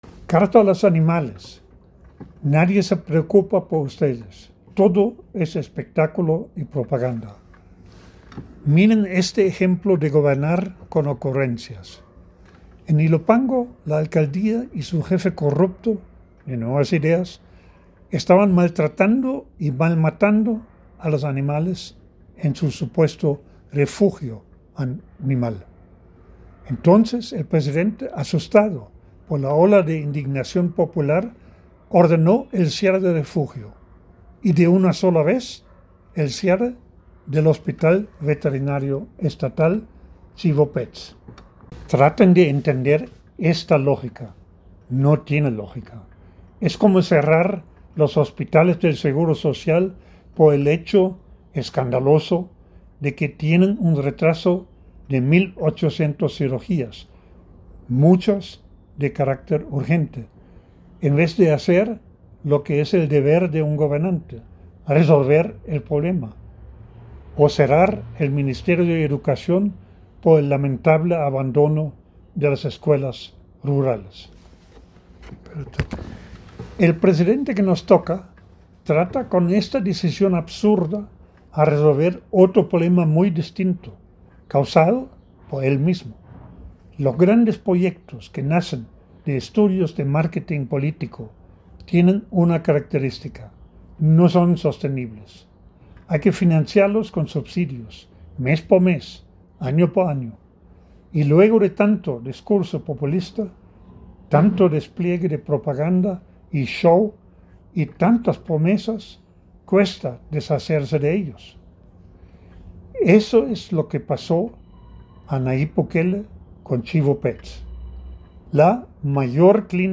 En la voz del autor: